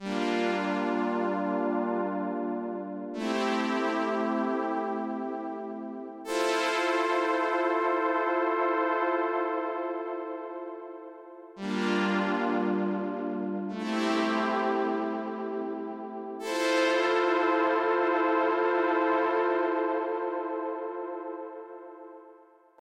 It adds a noticable bit of saturation whereas the digi delay sounds very basic in comparison.
This is with the parameters matched by value, not ear: